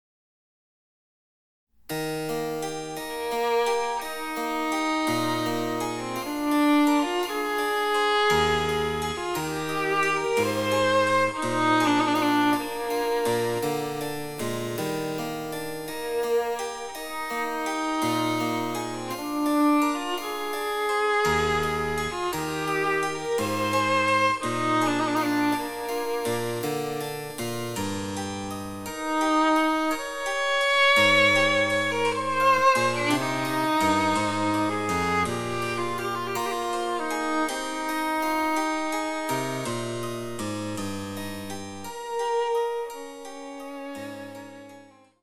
一転して、おだやかに淡々と語る音楽になります。伴奏のチェンバロはほぼ一貫して３連符を弾きます。
■ヴァイオリンによる演奏
チェンバロ（電子楽器）